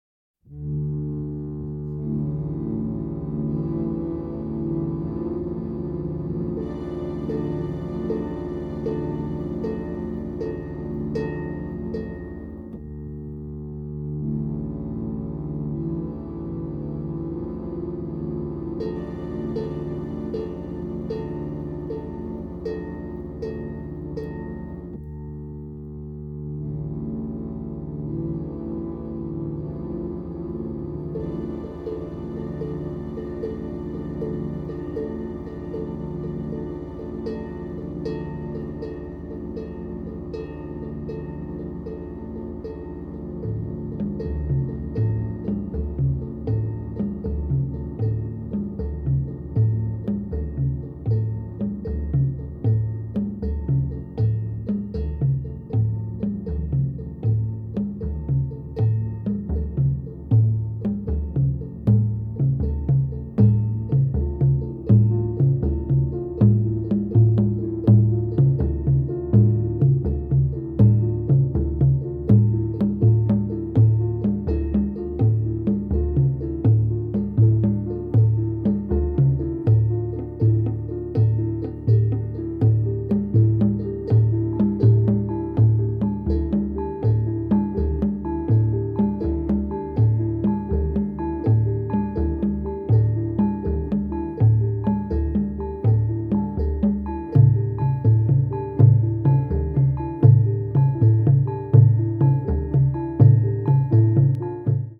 多彩な楽器の響きとリズムの絶妙なズレが独特のタイム感を産み出していて◎！